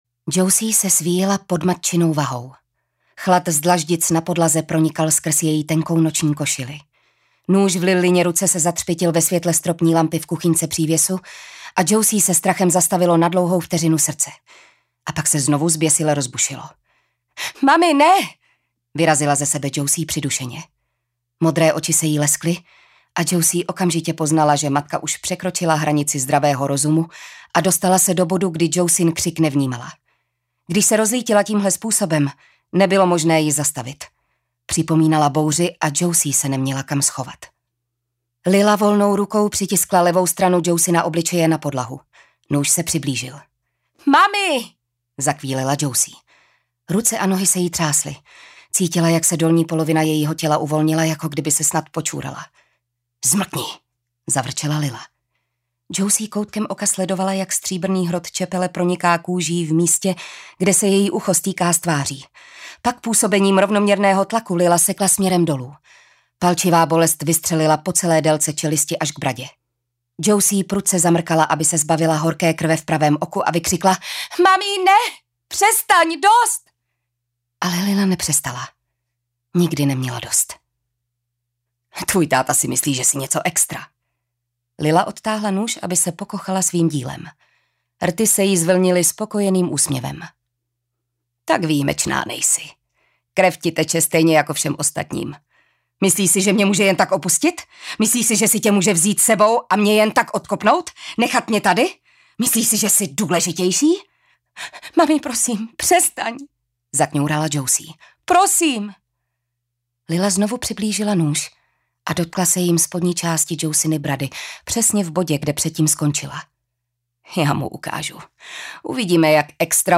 Audiokniha Zatmění obsahuje 13. díl oblíbené severské krimi s detektivem Harry Holem. Autor Jo Nesbø, čte David Matásek.